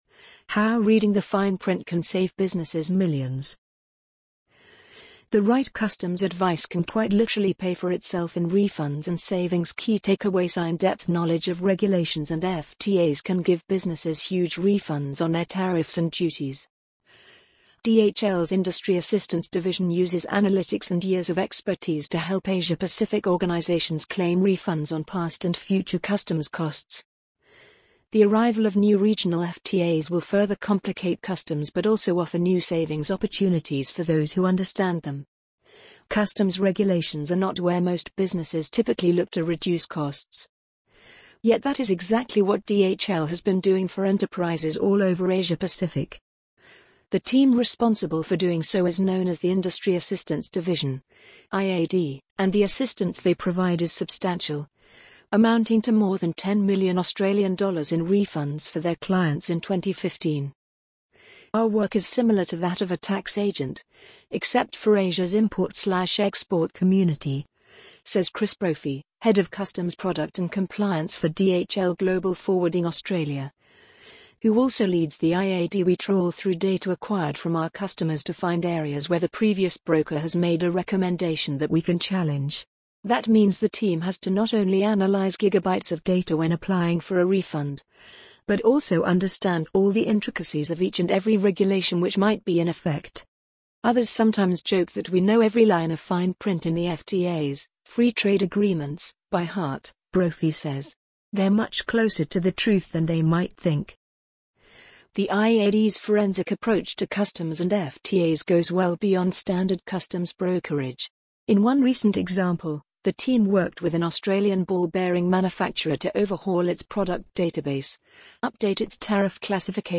amazon_polly_1225.mp3